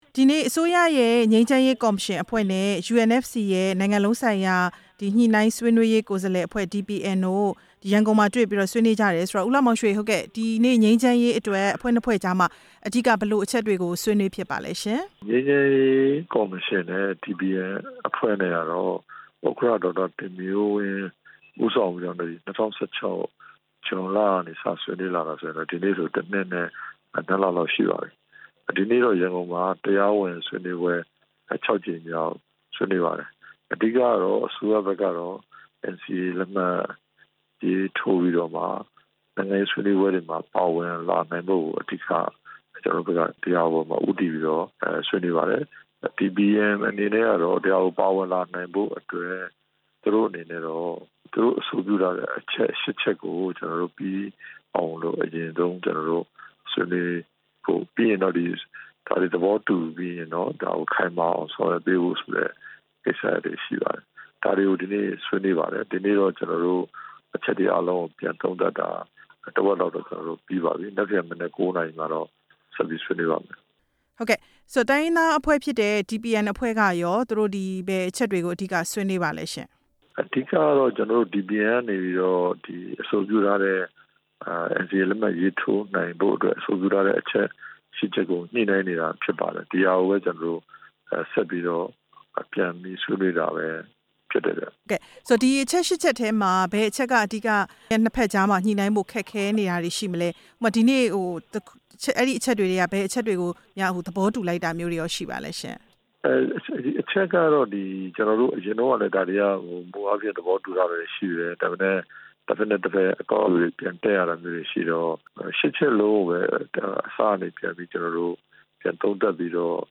အစိုးရငြိမ်းချမ်းရေးကော်မရှင် နဲ့ DPN တို့ ဆွေးနွေးပွဲအကြောင်း မေးမြန်းချက်